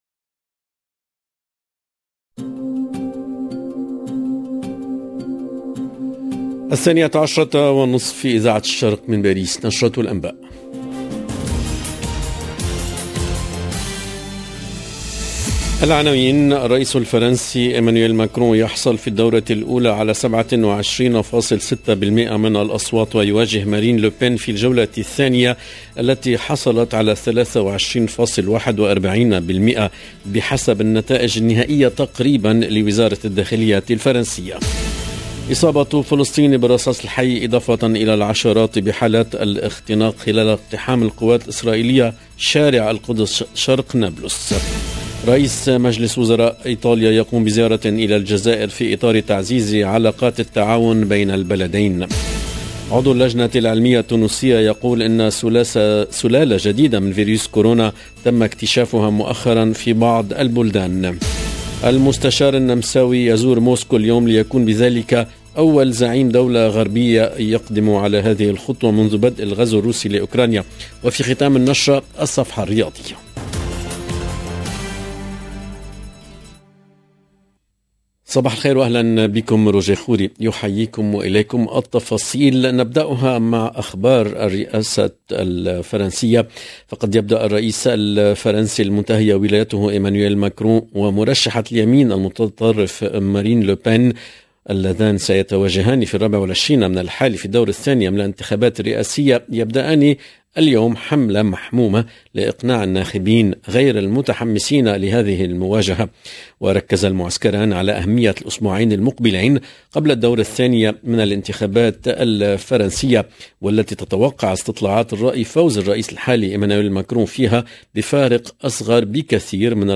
Présidentielle 11 avril 2022 - 17 min 33 sec LE JOURNAL DE MIDI 30 EN LANGUE ARABE DU 11/04/22 LB JOURNAL EN LANGUE ARABE الرئيس الفرنسي إيمانويل ماكرون يحصل في الدورة الاولى على 27.60٪ من الاصوات ويواجه مارين لوبان في الجولة الثانية التي حصلت على 23.41٪ بحسب النتائج النهائية تقريبا لوزارة الداخلية....